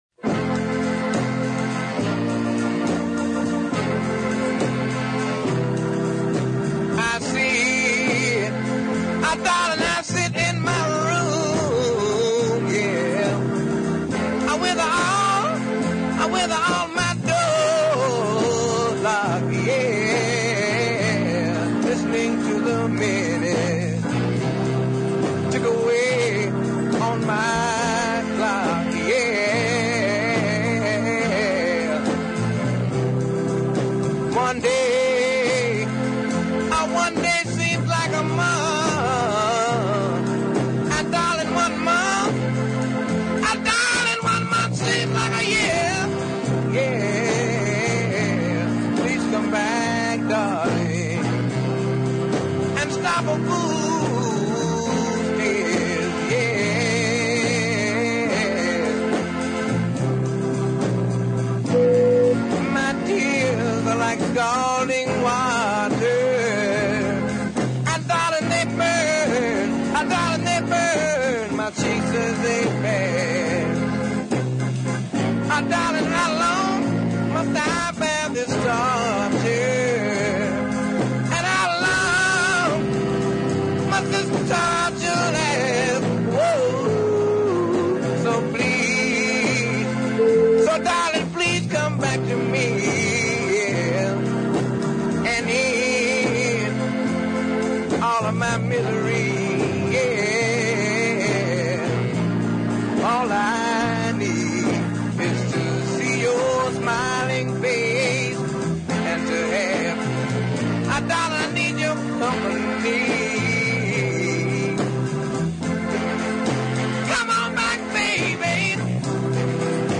Undoubteldy his masterpiece is the wonderful deep ballad
The recording is very "muddy"
There's an organ for sure and some horns but that's about it
Love the minor keyed bridge in the middle too.